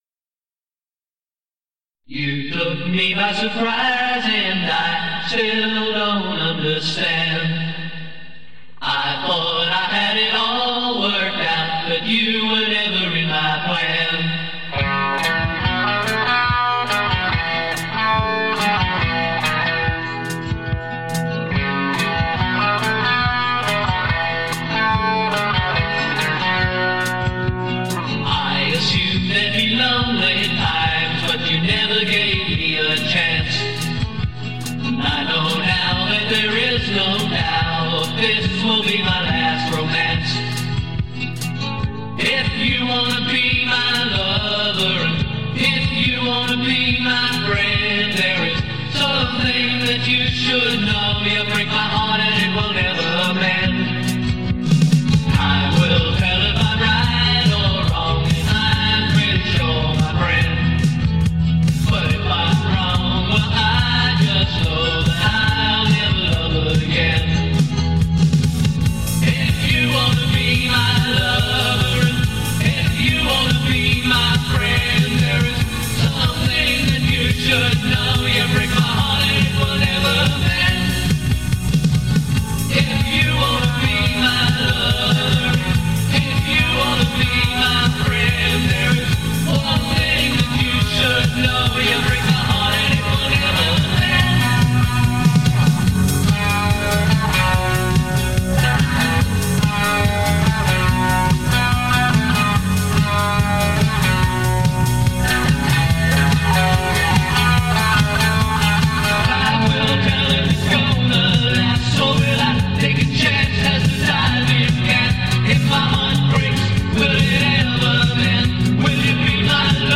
Recorded in Everton Park, Brisbane.